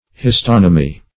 Search Result for " histonomy" : The Collaborative International Dictionary of English v.0.48: Histonomy \His*ton"o*my\, n. [Gr.